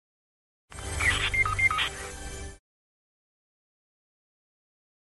Fortnite Radio Beep Battle Royale Sound Effect Free Download
Fortnite Radio Beep Battle Royale